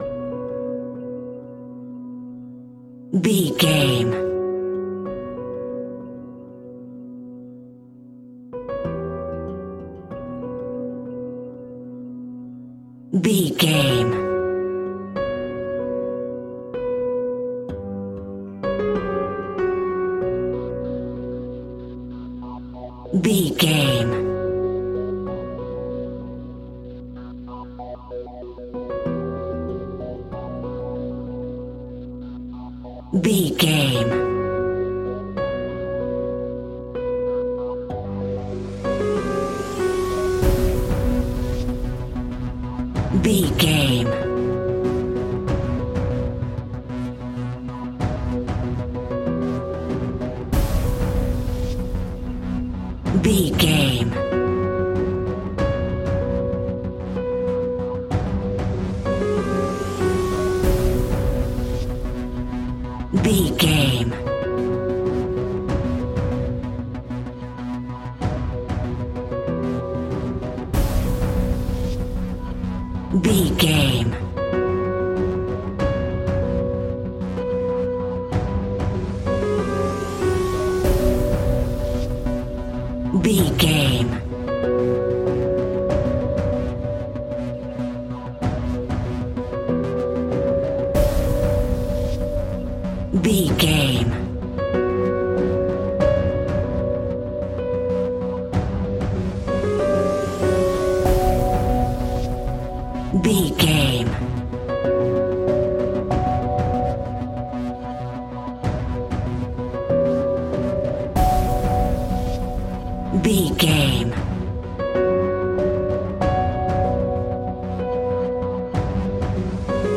Aeolian/Minor
ominous
dark
eerie
drums
percussion
synthesiser
tense
instrumentals
horror music